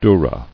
[dur·ra]